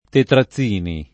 [ tetra ZZ& ni ]